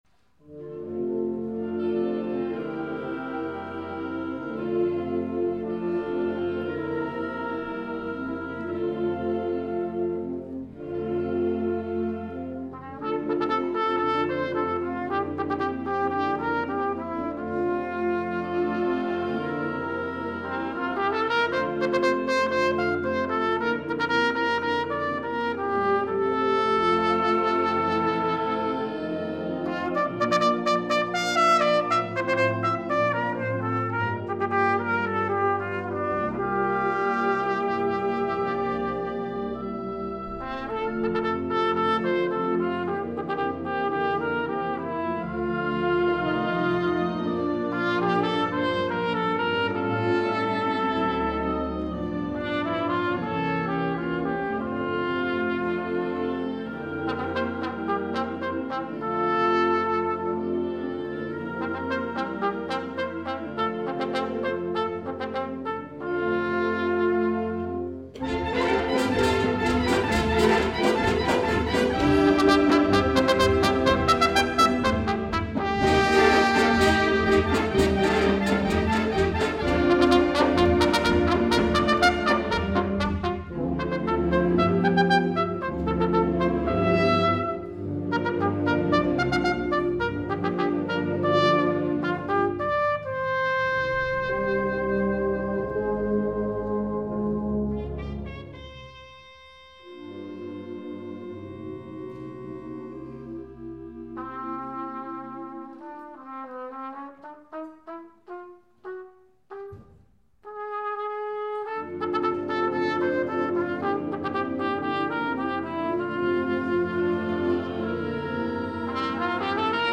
2012 Summer Concert